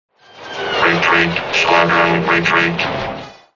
BSG Centurion - Retreat Squadron
BSG_Centurion-Retreat_Squadron.wav